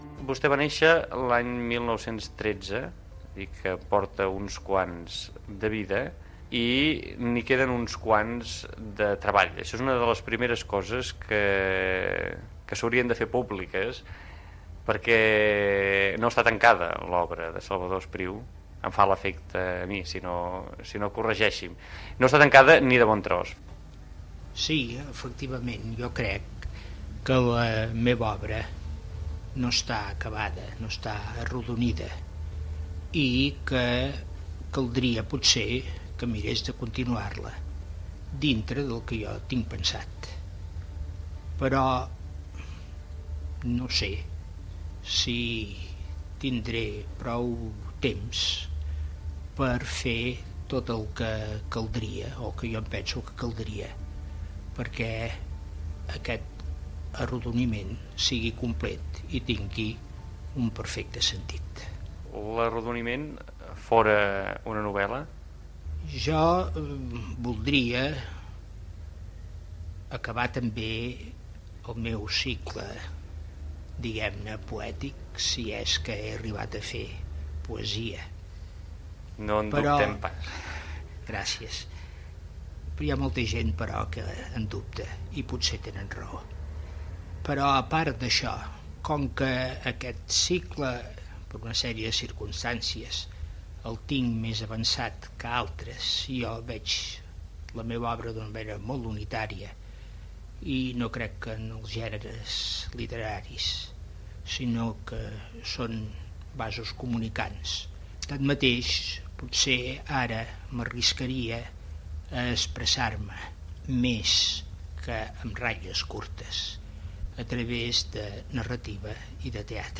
Entrevista al poeta Salvador Espriu.
Fragment extret del programa "Memòries de la ràdio" de Ràdio 4 emès el 5 d'octubre del 2010